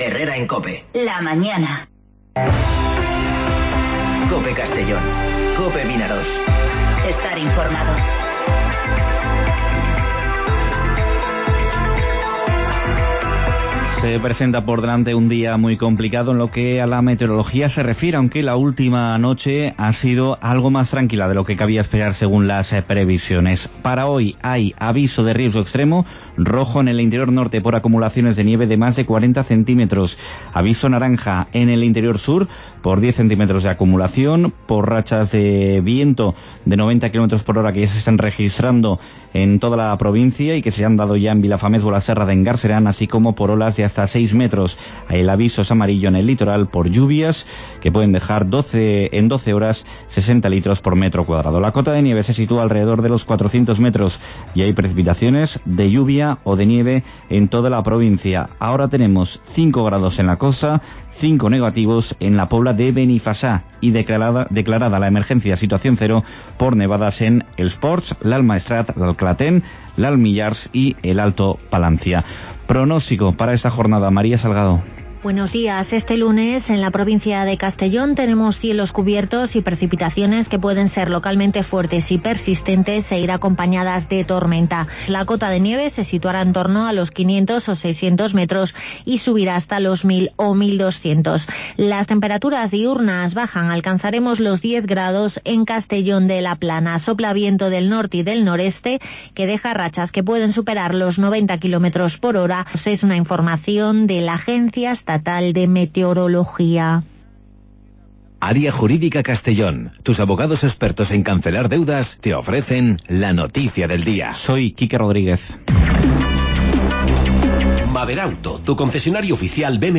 Informativo Herrera en COPE Castellón (20/01/2020)